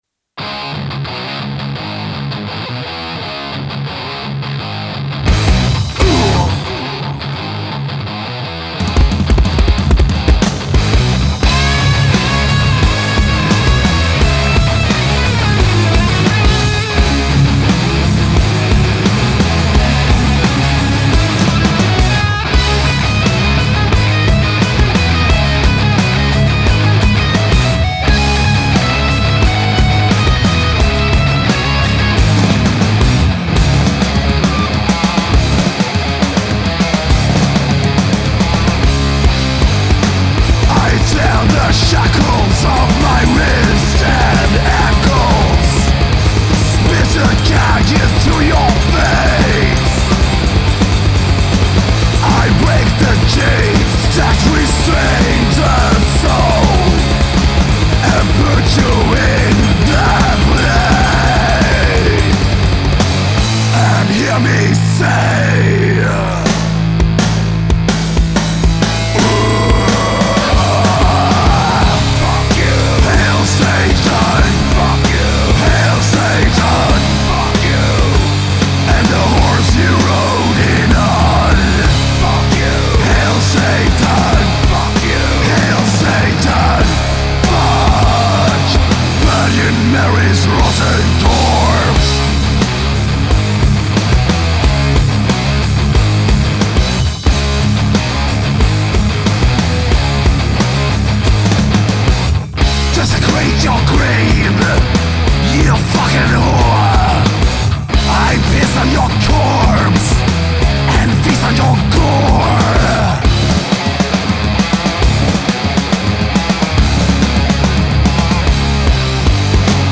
Genre: Metal
Besides, death metal really should be fun anyway.